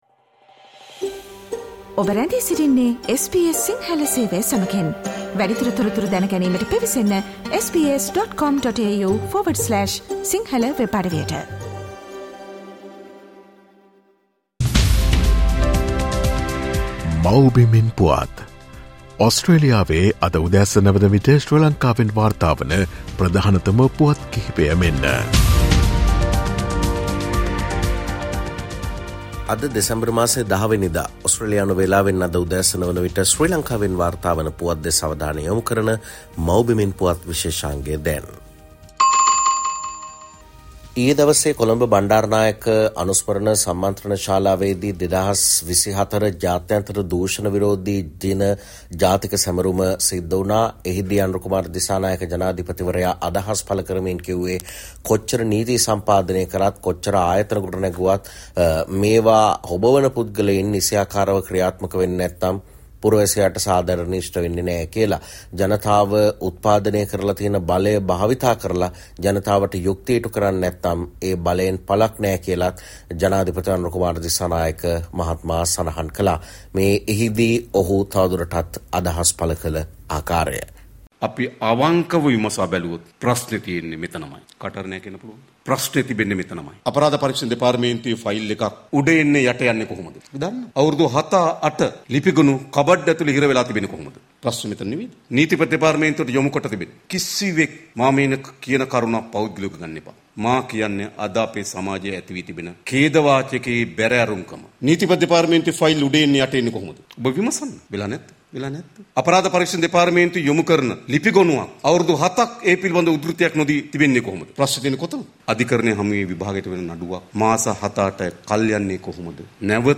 No matter how many rules are made, the bosses should work properly, says SL President: Homeland news 10 Dec